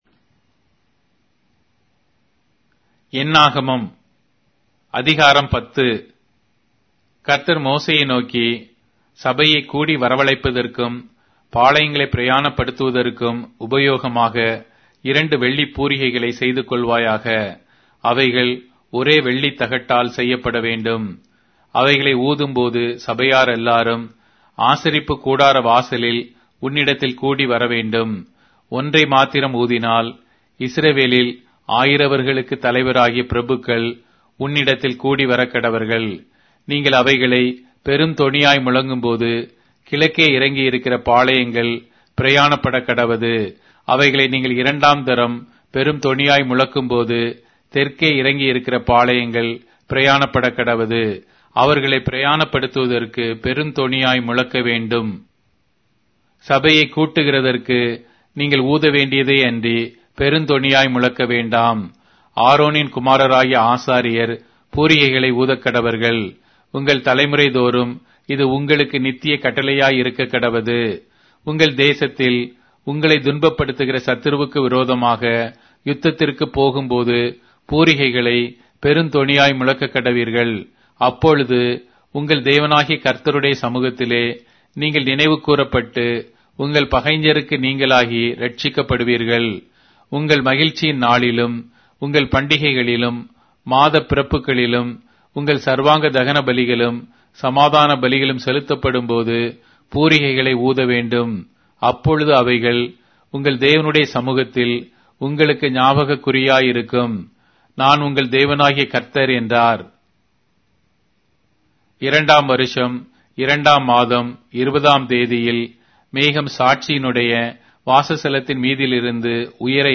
Tamil Audio Bible - Numbers 18 in Ervgu bible version